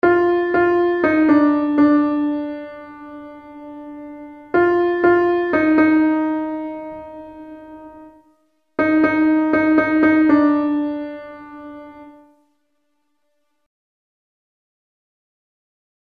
Key written in: B♭ Major
Type: Barbershop
Each recording below is single part only.